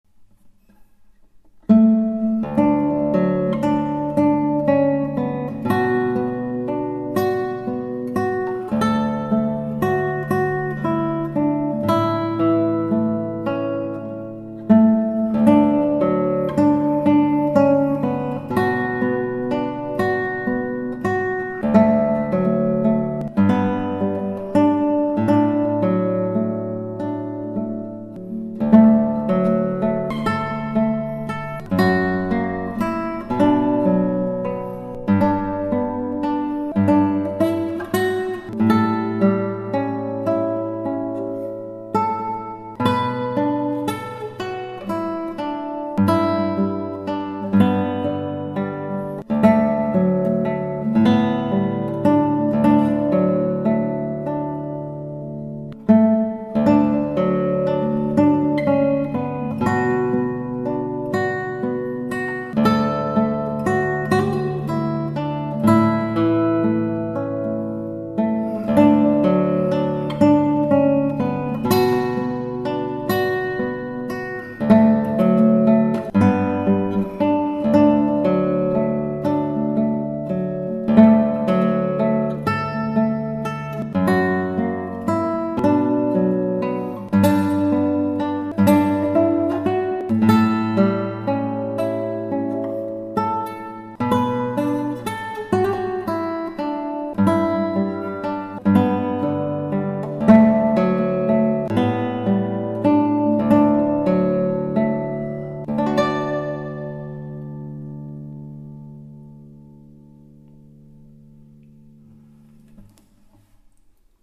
ギターは尾野ギター